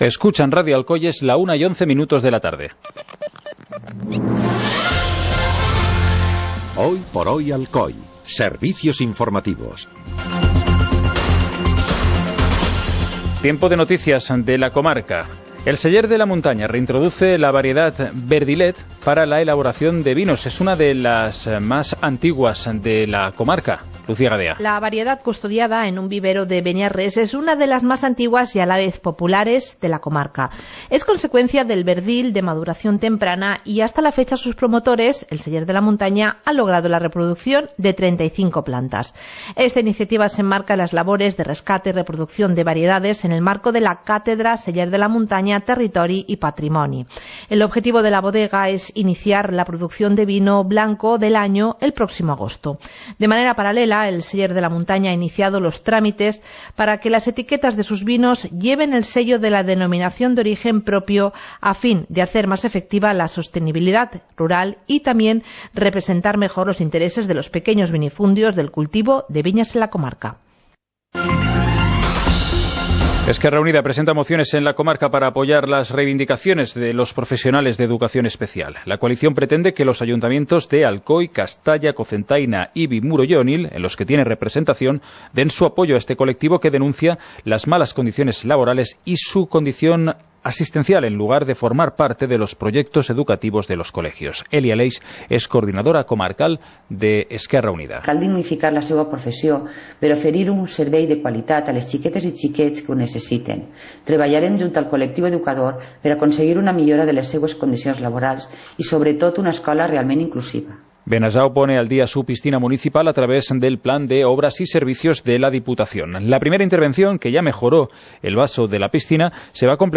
Informativo comarcal - martes, 12 de junio de 2018